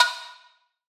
TH Kanye Perc.wav